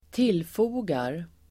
Uttal: [²t'il:fo:gar]